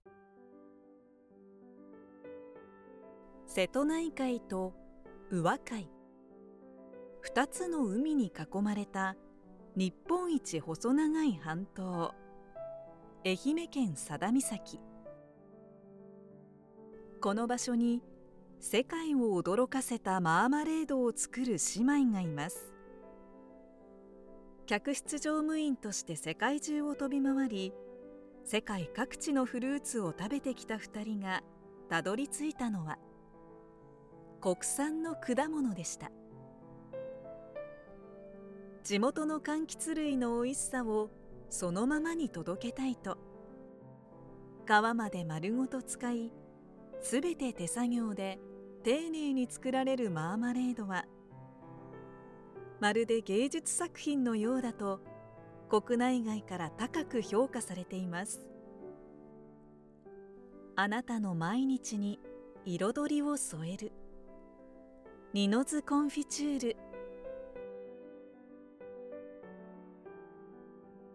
Sample Voice